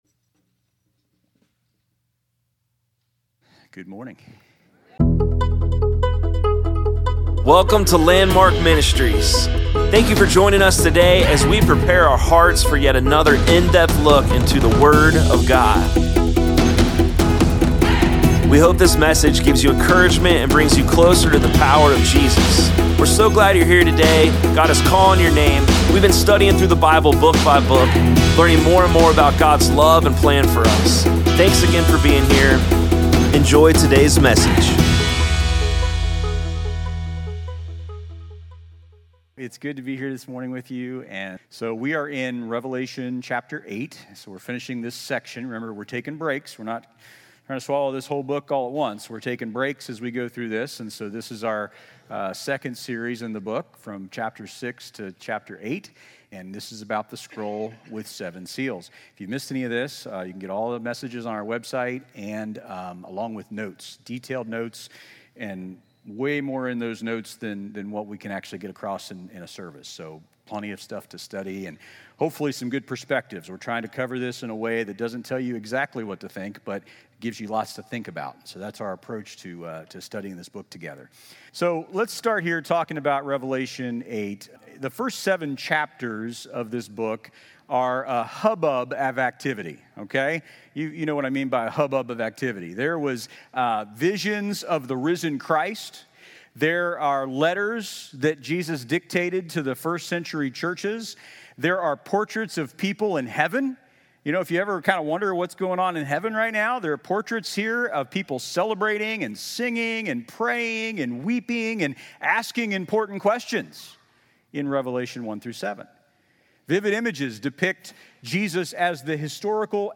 Sermons | Landmark Church of Clermont County, Ohio